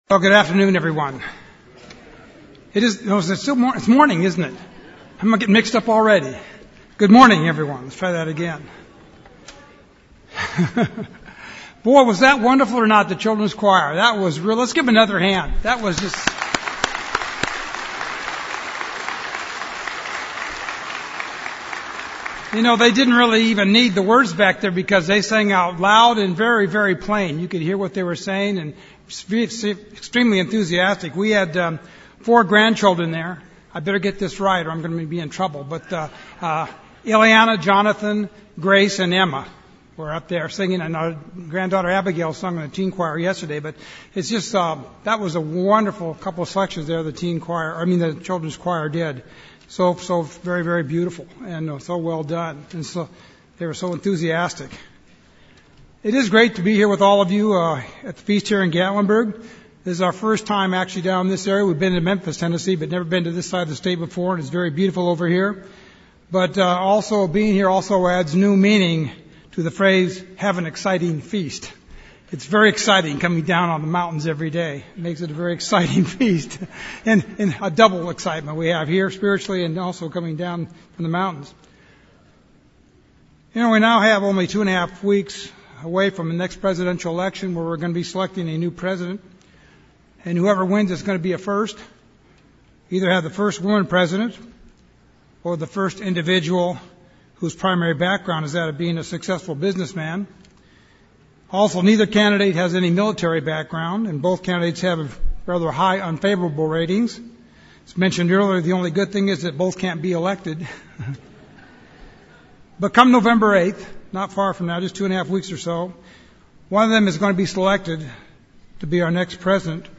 This sermon was given at the Gatlinburg, Tennessee 2016 Feast site.